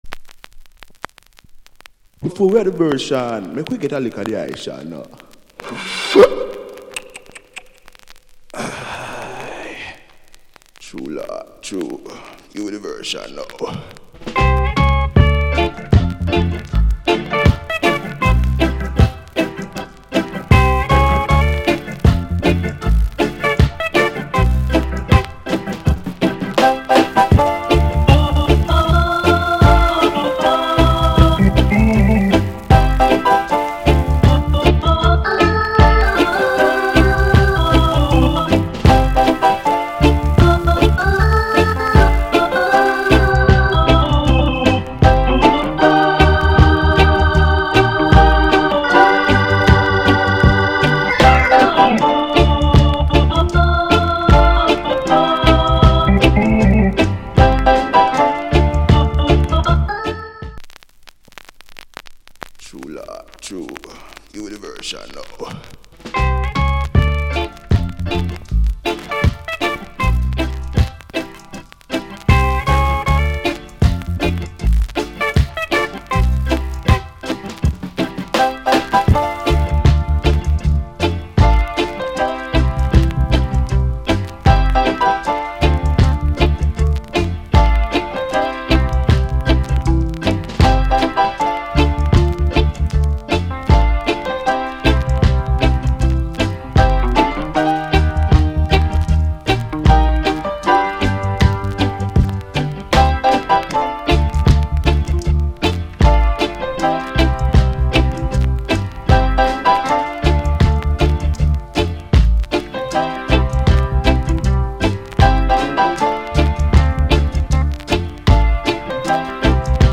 Genre Reggae70sEarly / Inst
Organ / Piano Inst cut！